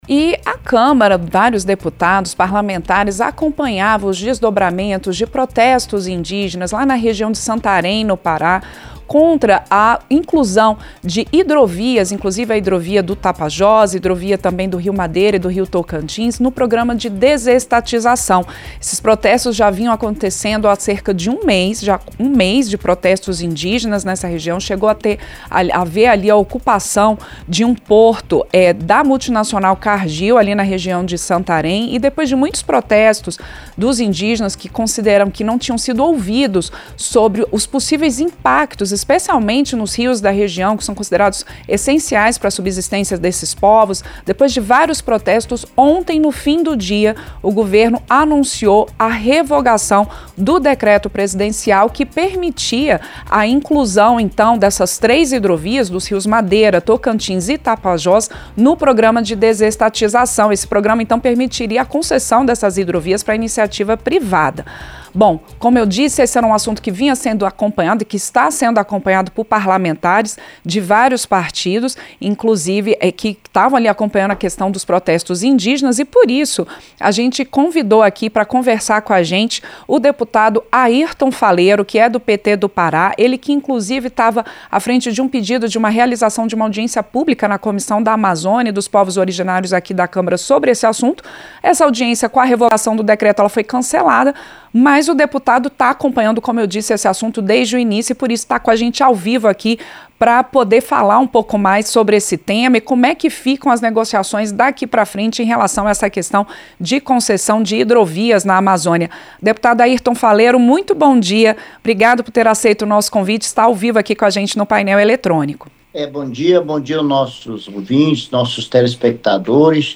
Entrevista - Dep. Airton Faleiro (PT-PA)